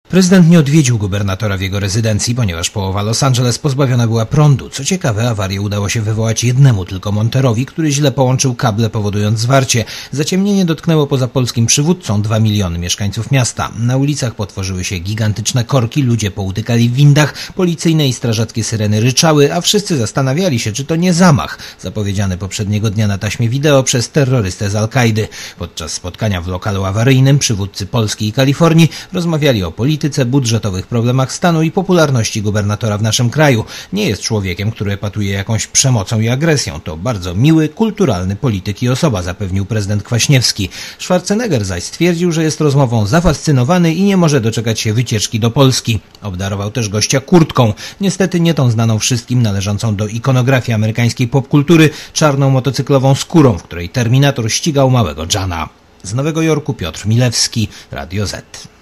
korespondenta Radia ZET w Nowym Jorku*